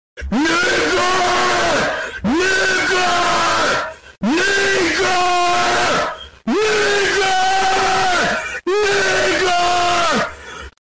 Sonido Tranquilizante Sound Effect Download: Instant Soundboard Button